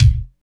31.08 KICK.wav